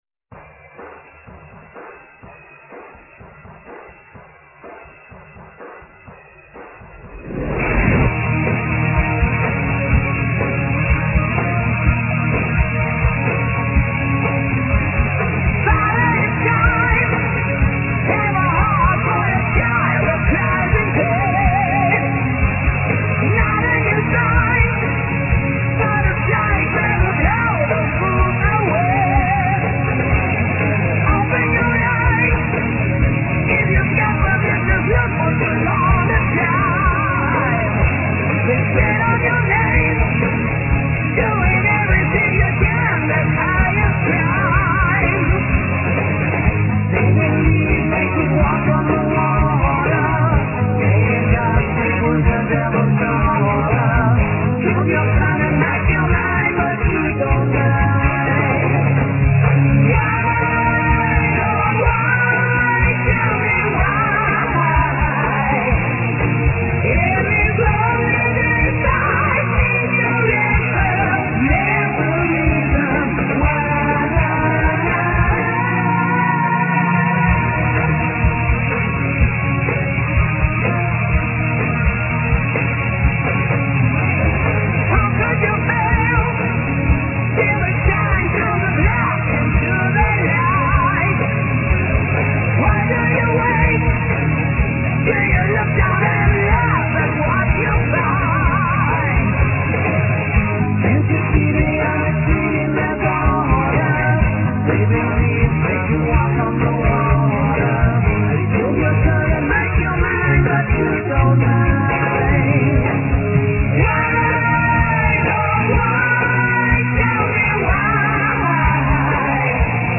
Vocals
Guitars
Bass
Drums
Keyboards